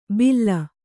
♪ billa